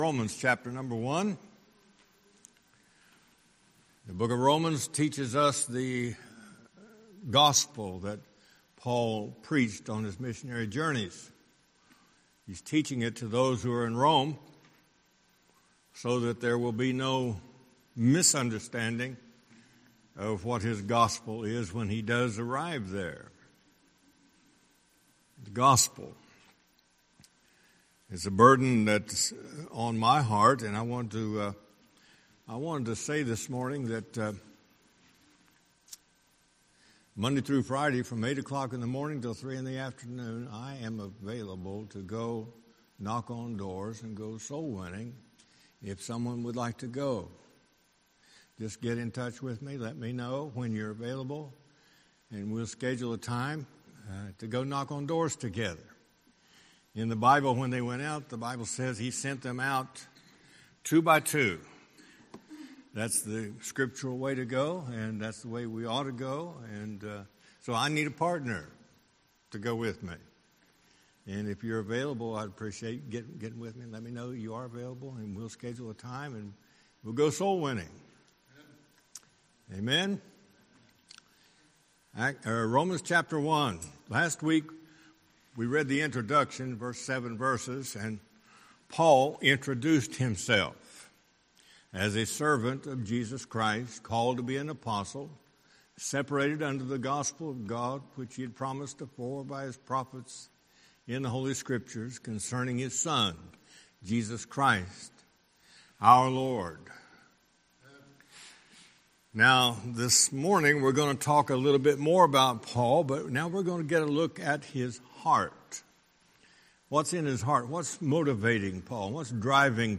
Sunday School Recordings
Series: Guest Speaker